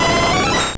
Cri de Voltali dans Pokémon Or et Argent.